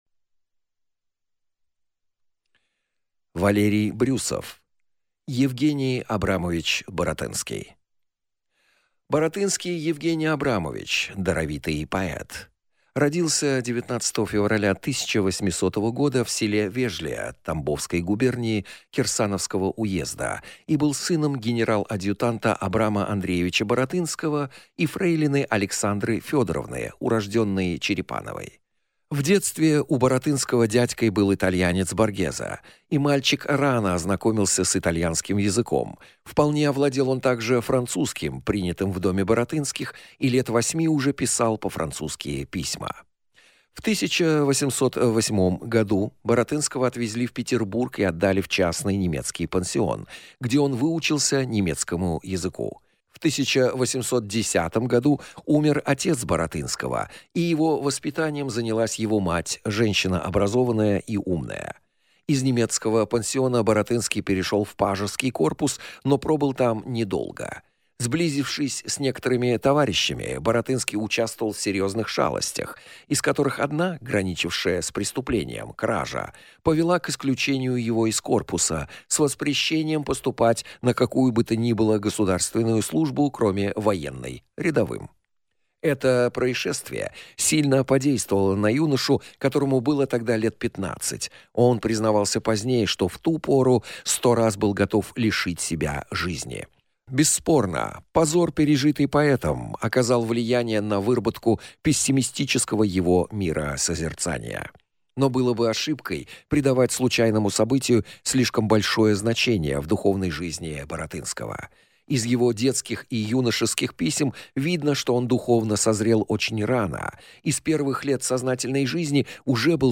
Аудиокнига Е. А. Баратынский | Библиотека аудиокниг